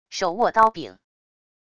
手握刀柄wav音频